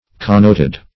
connoted - definition of connoted - synonyms, pronunciation, spelling from Free Dictionary
connote \con*note"\ (k[o^]n*n[=o]t"), v. t. [imp.